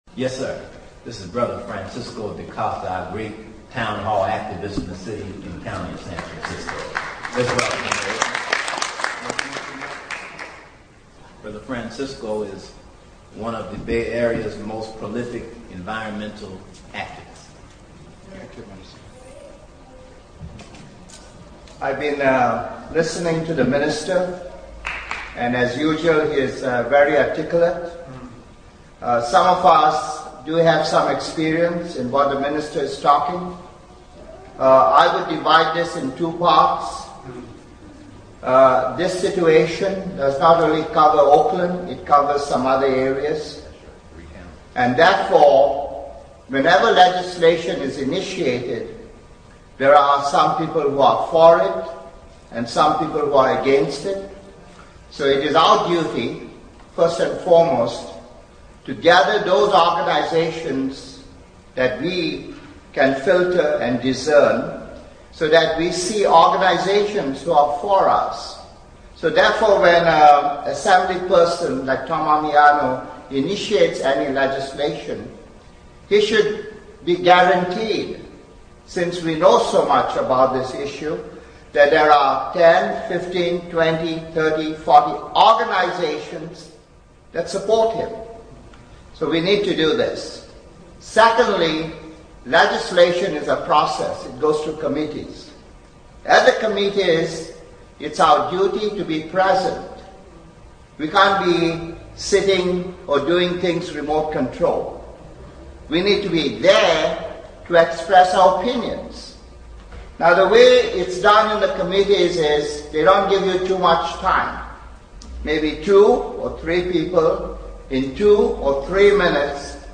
Town Hall for Justice for Oscar Grant, Oakland, 8/29/09: audio